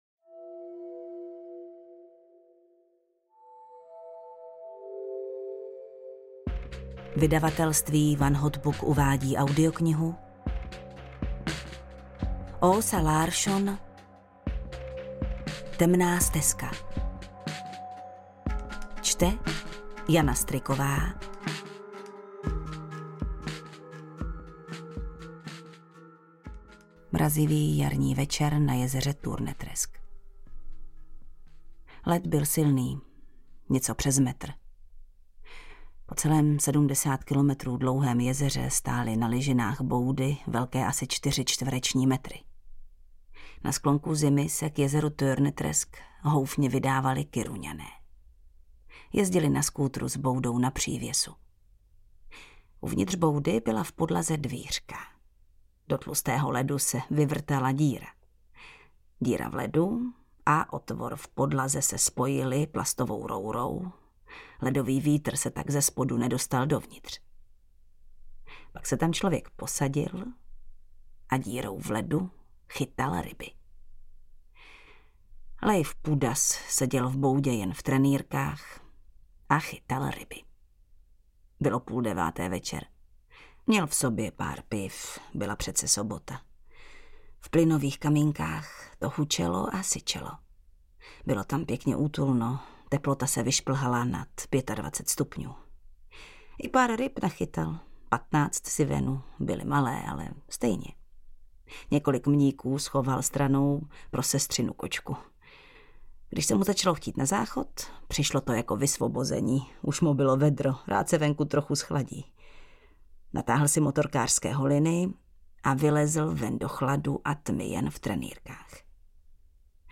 Temná stezka audiokniha
Ukázka z knihy
• InterpretJana Stryková